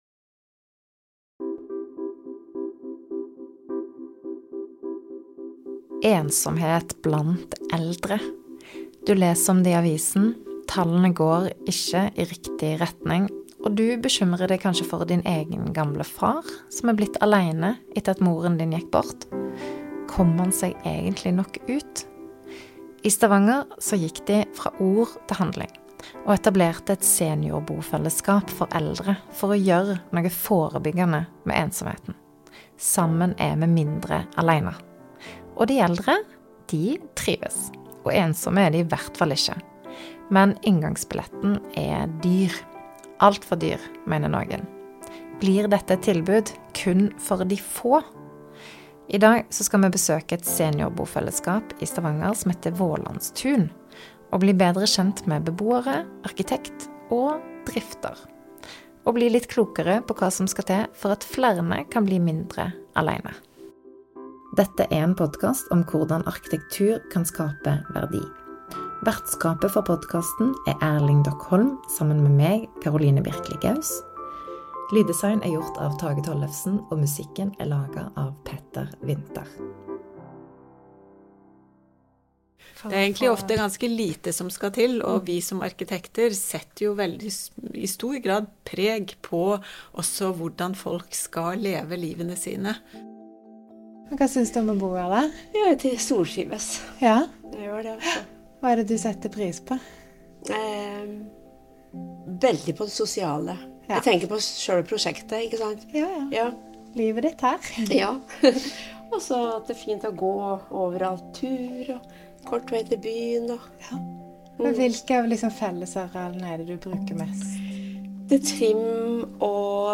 I dag besøker vi Vålandstun for å bli bedre kjent med beboerne, arkitektene og de som driver fellesskapet, og diskuterer hva som skal til for at flere eldre kan få et fellesskap og mindre ensomhet.
Beboere på Vålandstun